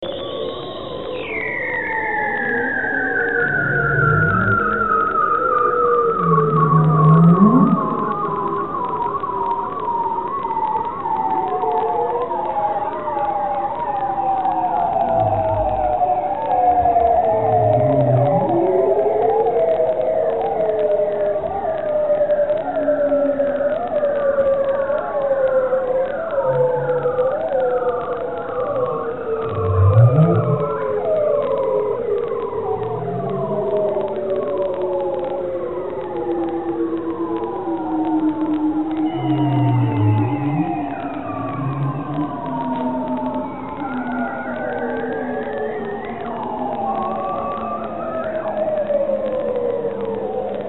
Ice Seal Vocalizations - Ocean Noise
The vocalizations of “ice seals”, a bearded seal in this case, use complex tones and wide ranges of frequencies. Yet some of the simpler sounds created by ships passing through their environment may have the effect of masking their communications as one sound rolls over and interferes with the other.
Bearded_Seal.mp3